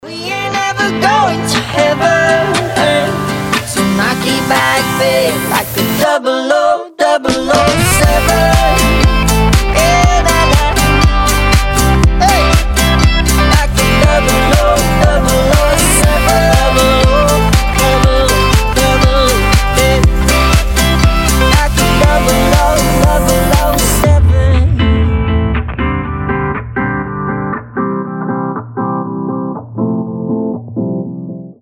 • Качество: 320, Stereo
гитара
позитивные
мужской вокал
веселые
заводные
Dance Pop